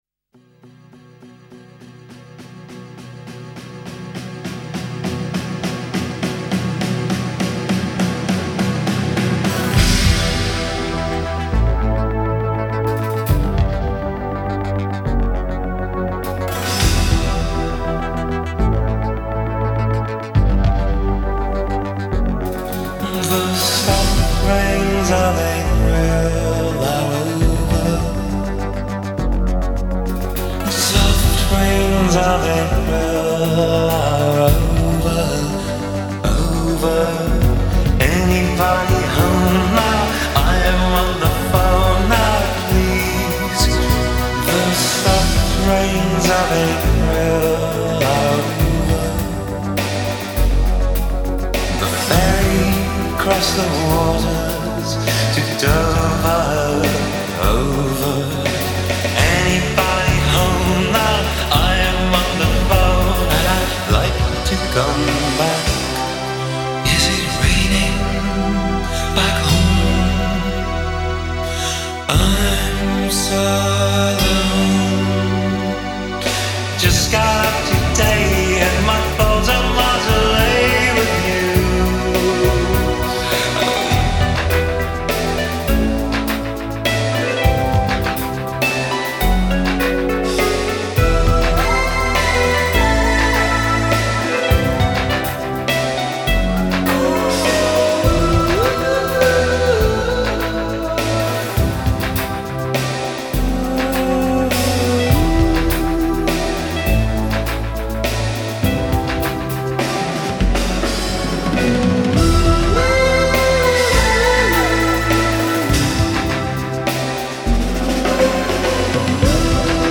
Canzone atipica